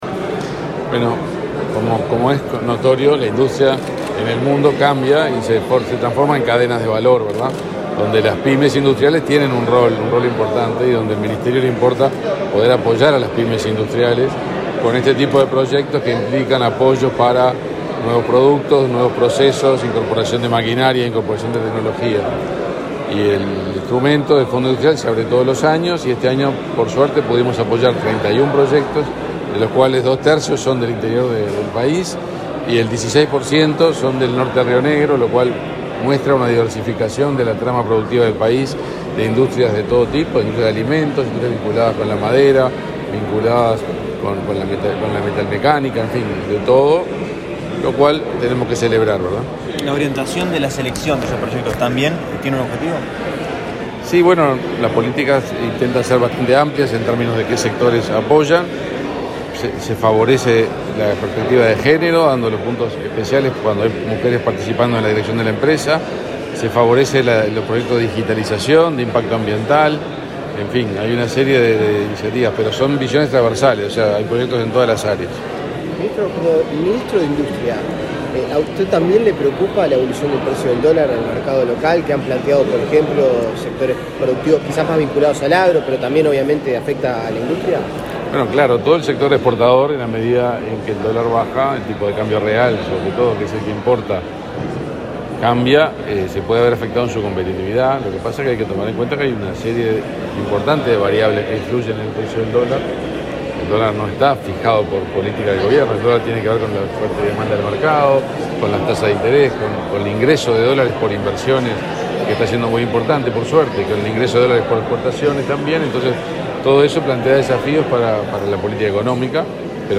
Declaraciones del ministro de Industria, Energía y Minería, Omar Paganini
Este viernes 16, el ministro Omar Paganini, el subsecretario Walter Verri, el presidente del Laboratorio Tecnológico del Uruguay, Ruperto Long, y la directora de Industrias, Susana Pecoy, participaron de la entrega de certificados a proyectos seleccionados por la convocatoria 2022 del Fondo Industrial. Luego, Paganini dialogó con la prensa.